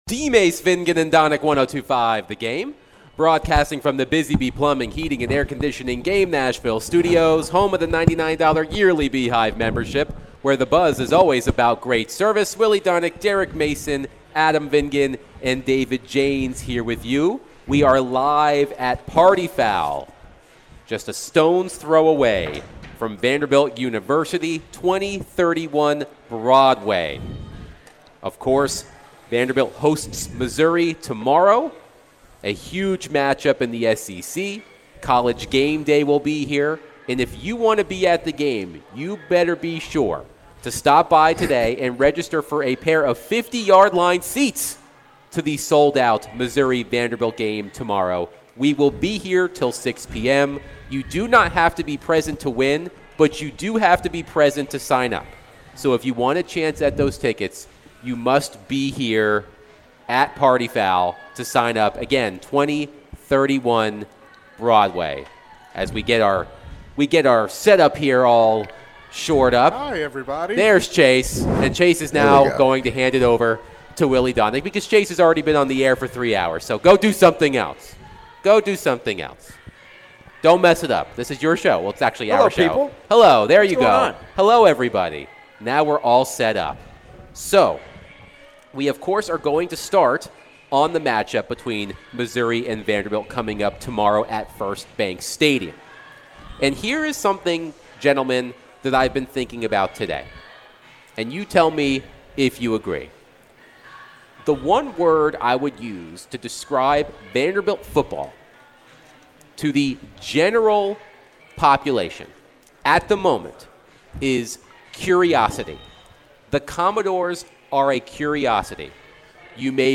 The guys ask the question, When was the last time there was this much buzz about a sporting event in Nashville? They got reactions and calls